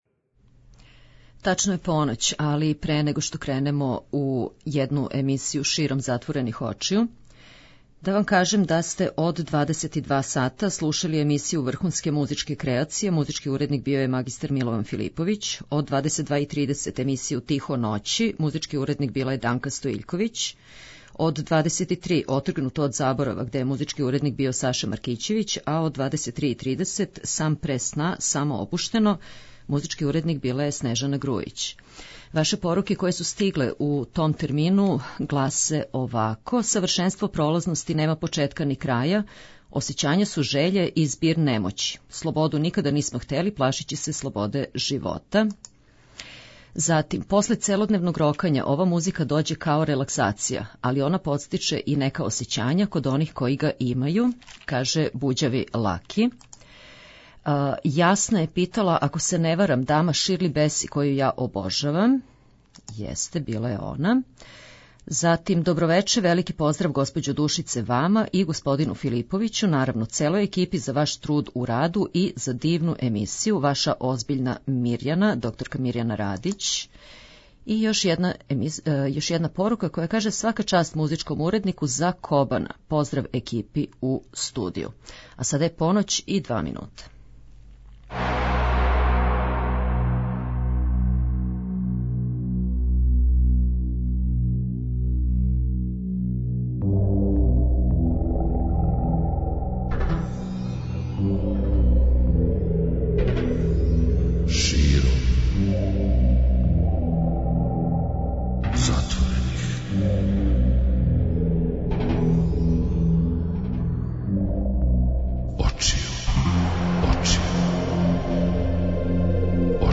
Још једну ноћ дружићемо се и сарађивати у избору најлепших женских и мушких вокала са наше и иностране сцене, све до раних јутарњих сати.
преузми : 57.04 MB Широм затворених очију Autor: Београд 202 Ноћни програм Београда 202 [ детаљније ] Све епизоде серијала Београд 202 We care about disco!!!